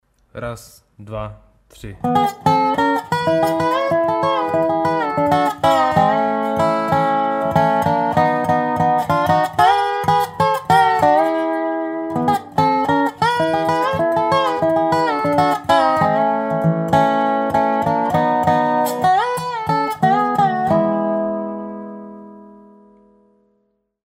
Nástroj: Dobro
Tónina: E
Střední Styl: Bluegrass Ladění